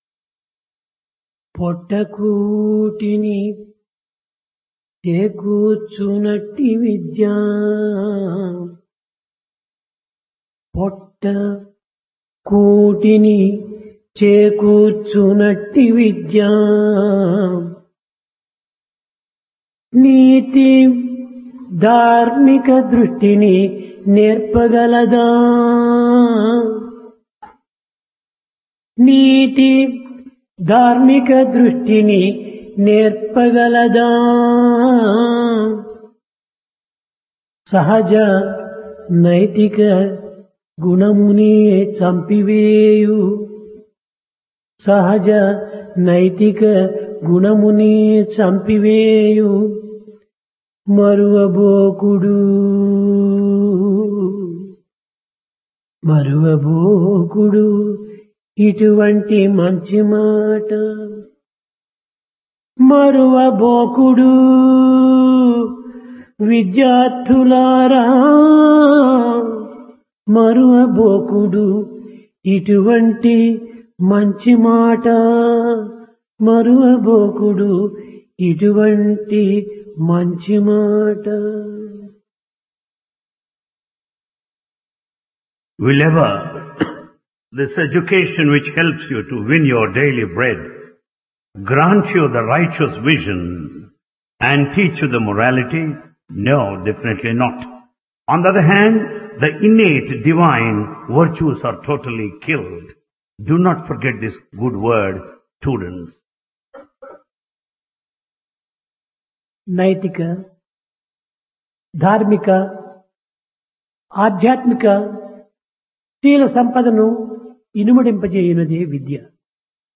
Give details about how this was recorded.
Place Prasanthi Nilayam